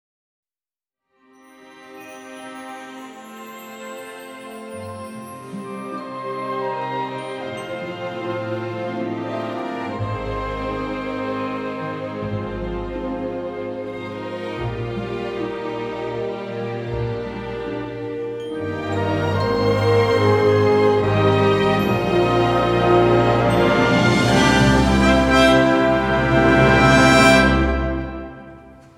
Звук заставки